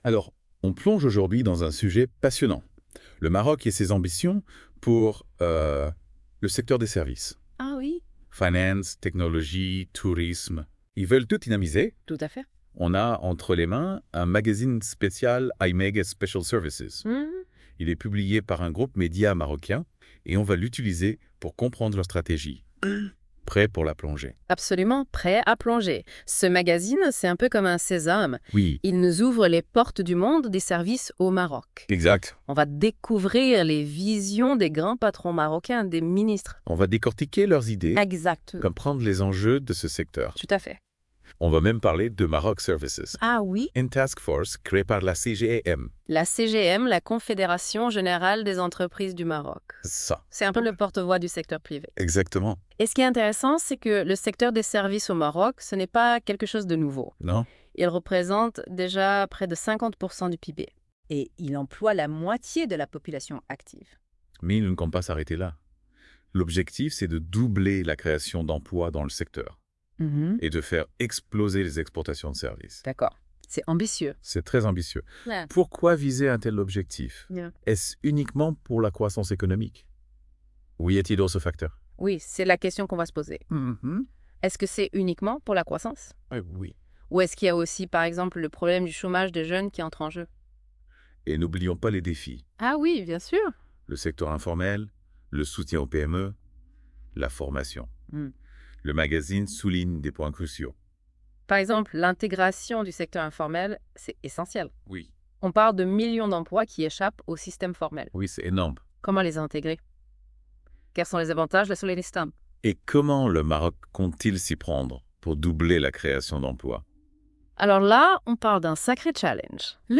Les chroniqueurs de la Web Radio R212 ont lus attentivement ce I-MAG Spécial Stress Hydrique de L'ODJ Média et ils en ont débattu dans ce podcast